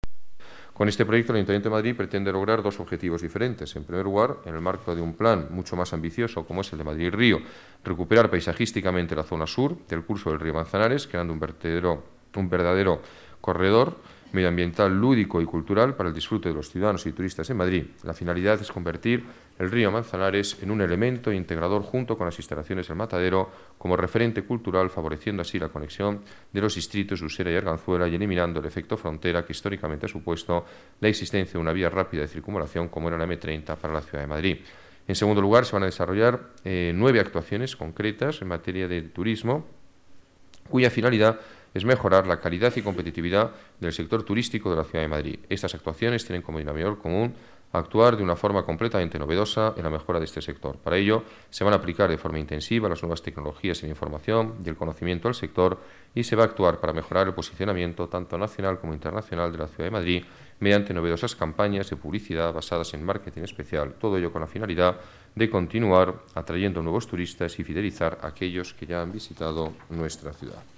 Nueva ventana:Declaraciones del alcalde de Madrid, Alberto Ruiz-Gallardón: 39 millones de euros para impulsar Madrid-Río y el turismo